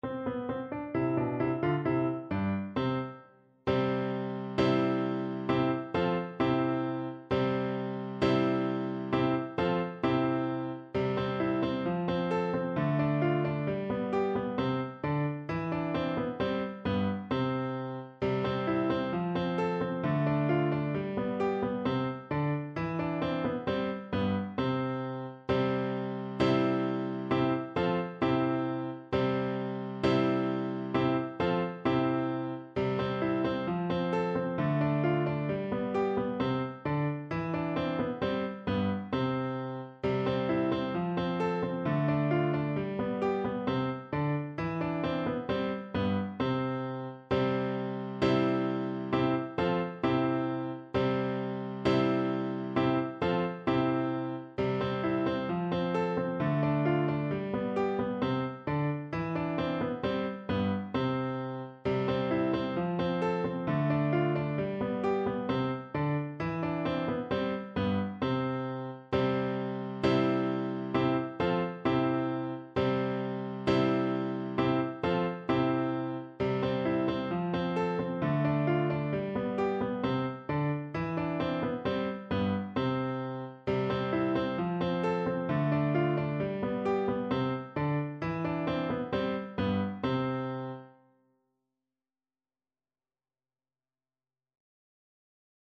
kolęda: Przybieżeli do Betlejem (na klarnet i fortepian)
Symulacja akompaniamentu